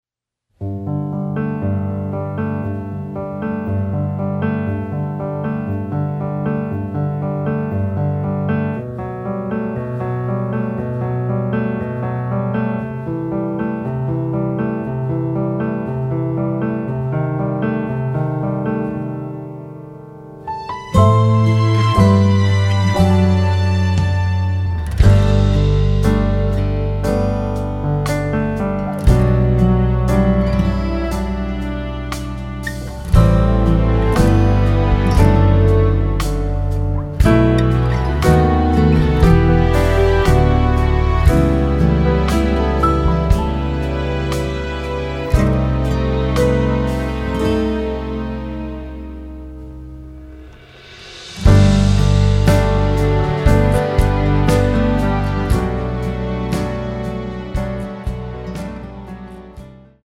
키 G 가수
원곡의 보컬 목소리를 MR에 약하게 넣어서 제작한 MR이며